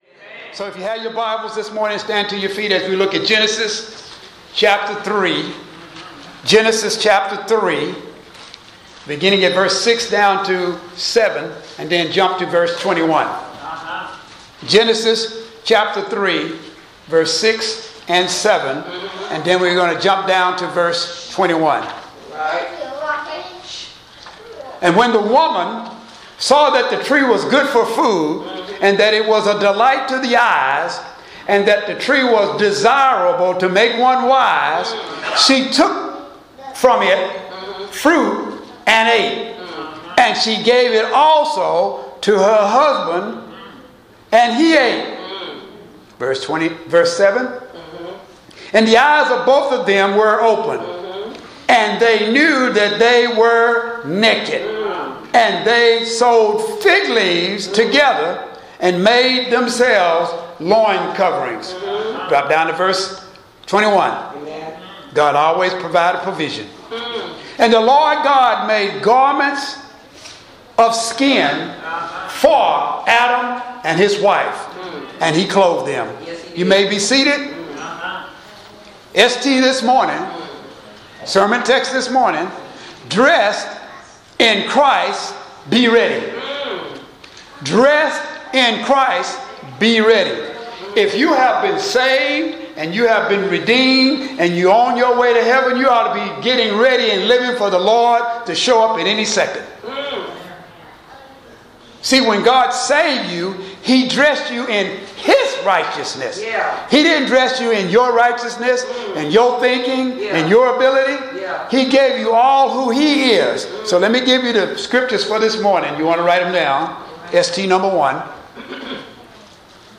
Posted in Audio Sermons